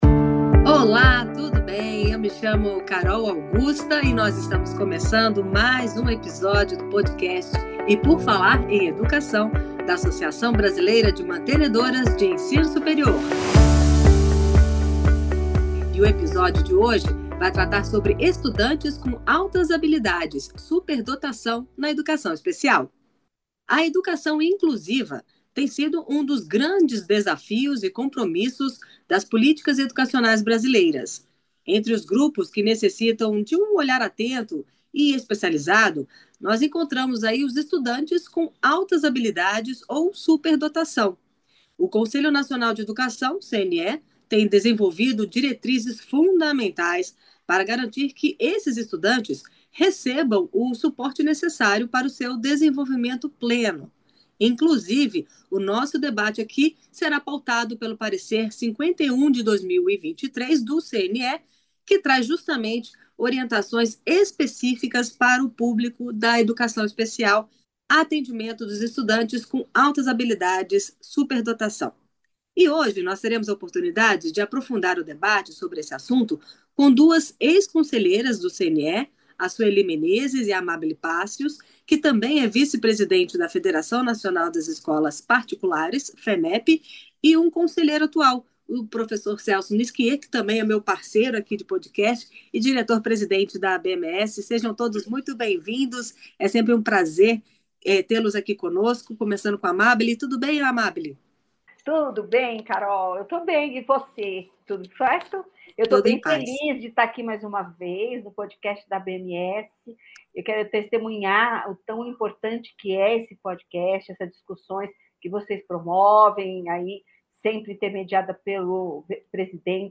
O tema foi debatido neste episódio por Suely Menezes e Amábile Pacios, ex-conselheiras do CNE, além de Celso Niskier, conselheiro atual e presidente da ABMES.